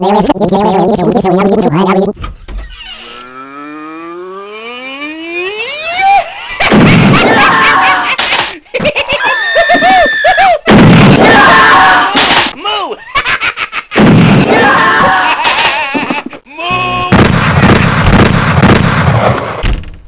Psycow Sound wave of a Cow that fights back.